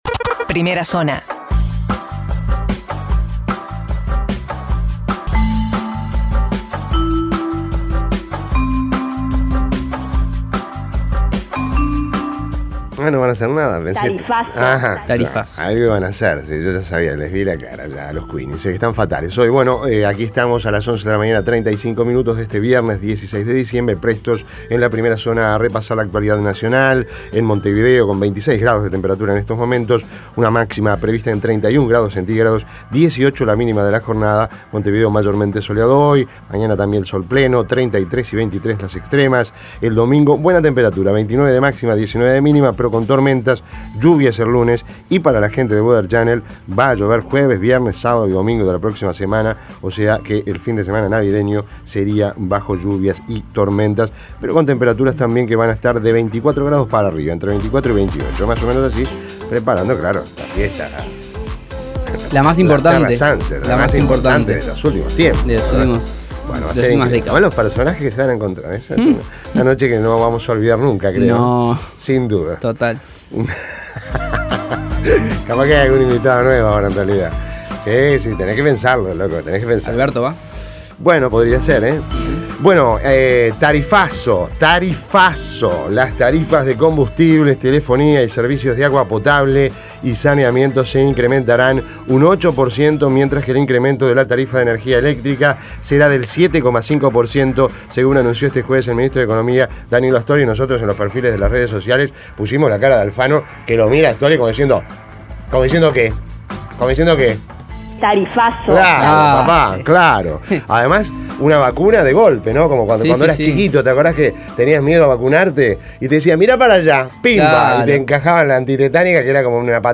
Resumen de noticias Primera Zona Imprimir A- A A+ Las principales noticias del día, resumidas en la Primera Zona de Rompkbzas.